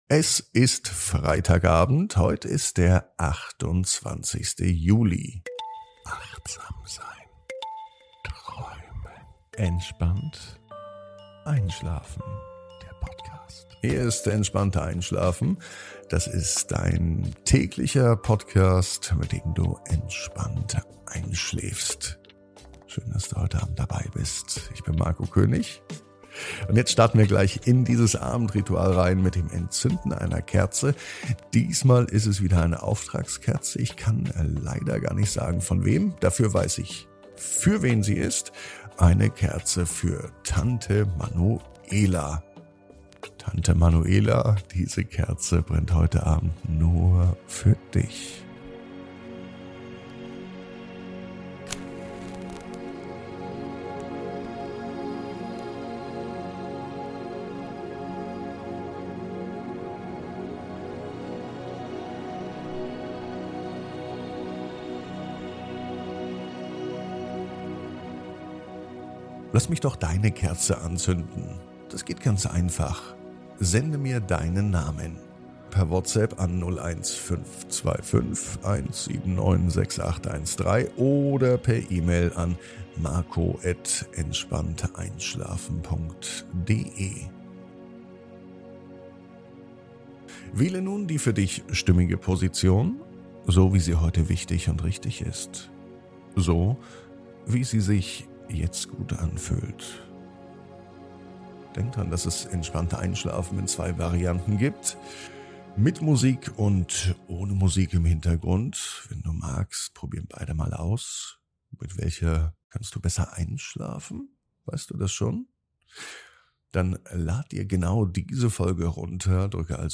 (ohne Musik) Entspannt einschlafen am Freitag, 28.07.23 ~ Entspannt einschlafen - Meditation & Achtsamkeit für die Nacht Podcast